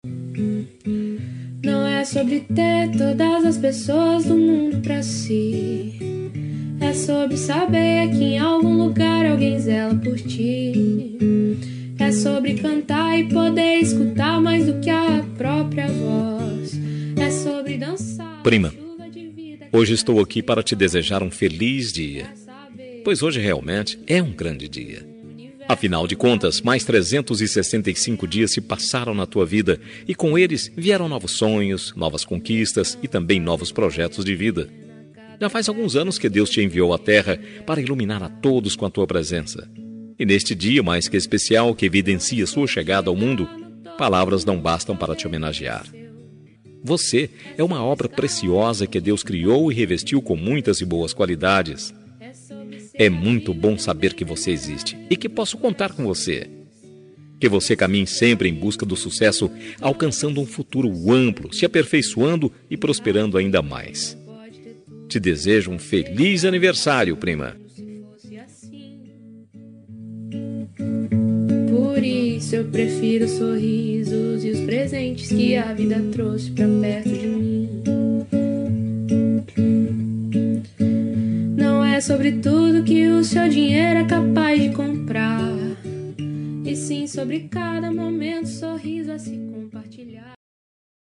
Aniversário de Prima – Voz Masculina – Cód: 042813